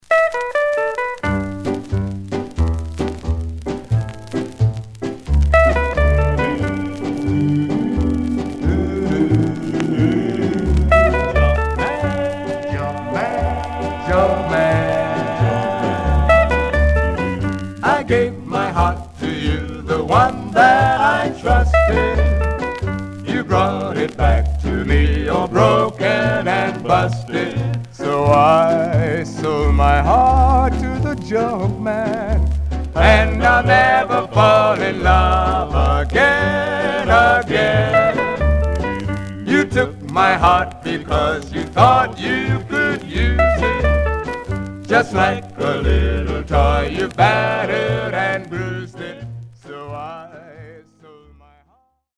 Some surface noise/wear
Mono
Male Black Groups